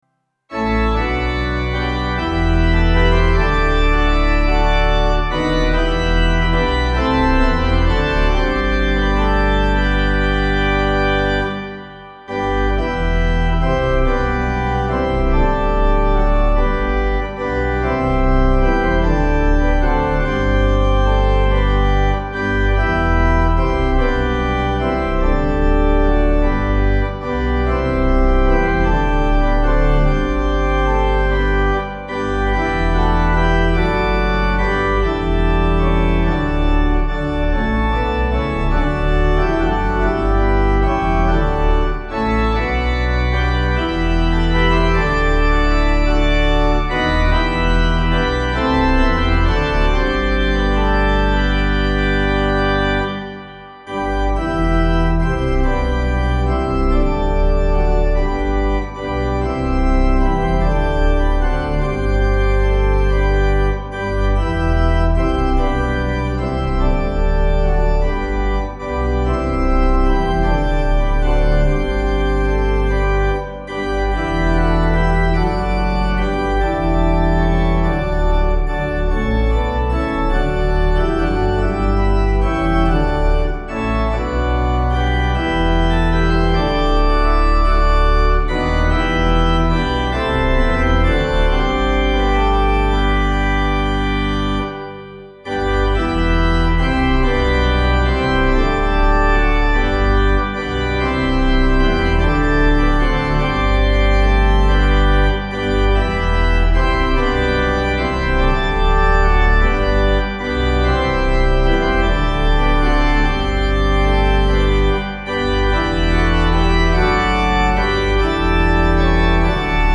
organpiano